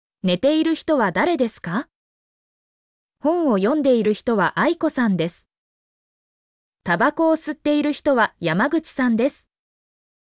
mp3-output-ttsfreedotcom-19_QqmZeLNN.mp3